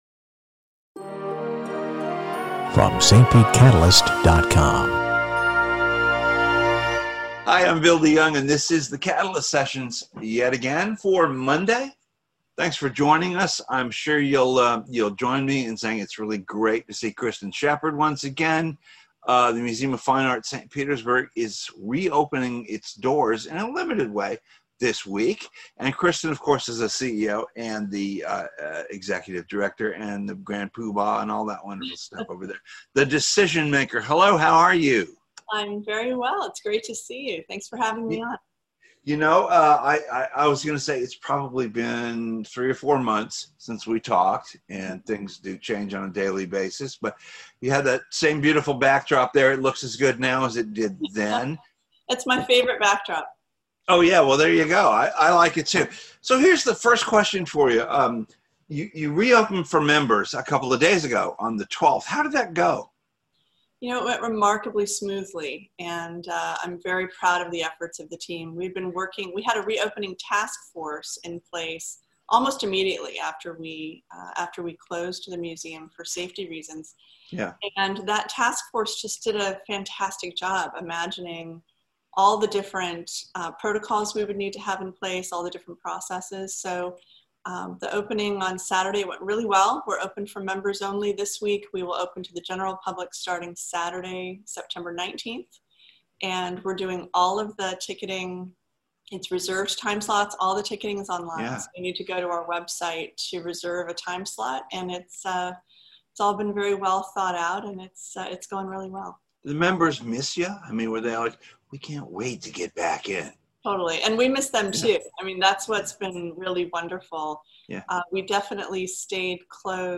Other topics in the wide-ranging interview include the sad final days of the expansive Art of the Stage exhibit and performance series, the new exhibitions (from contemporary artist Derrick Adams, and a collection of historic photography) and the tricky business of booking on-loan exhibits .